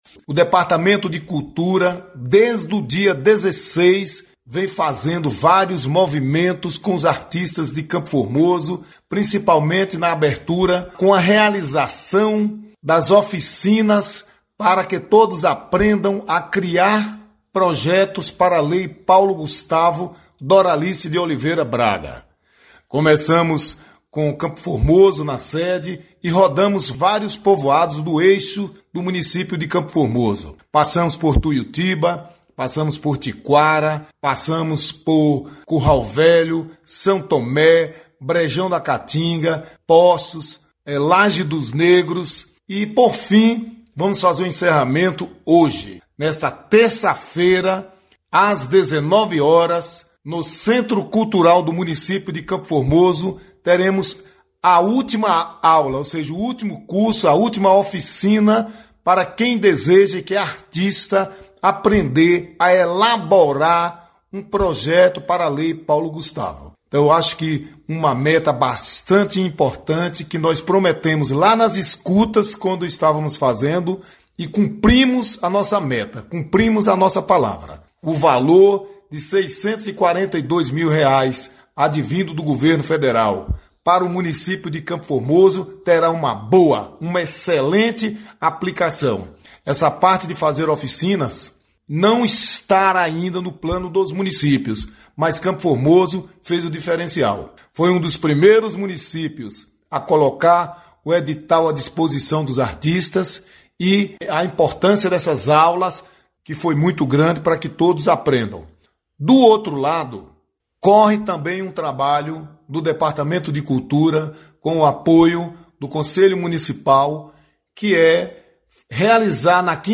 Secretário de cultura Joel de Sena explica o encerramento de oficinas da lei Paulo Gustavo